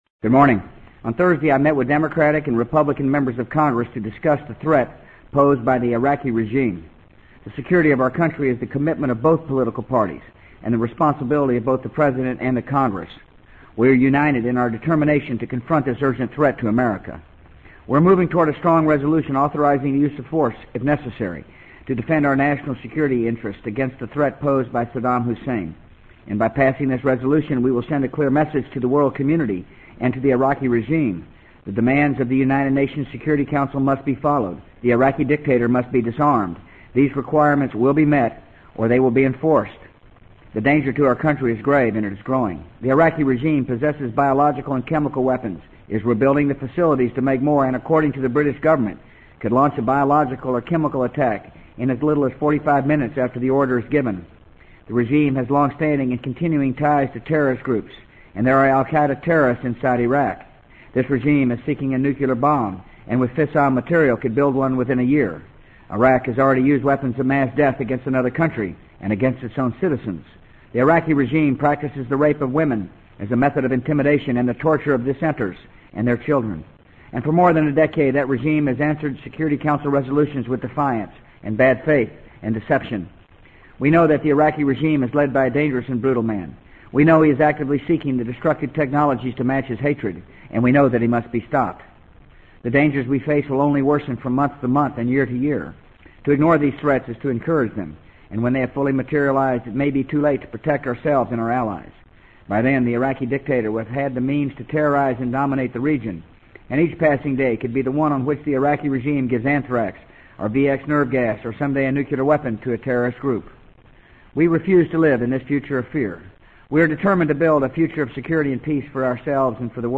【美国总统George W. Bush电台演讲】2002-09-28 听力文件下载—在线英语听力室